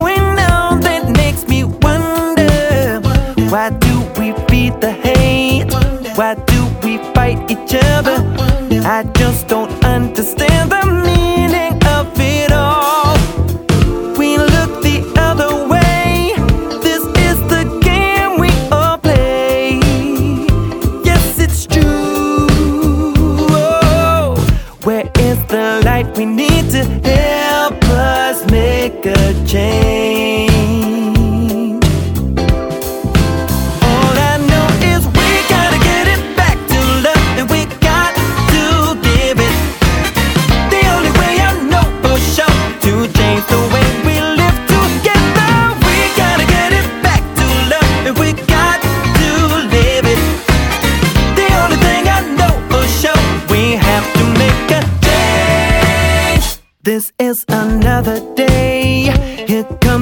• Sachgebiet: Pop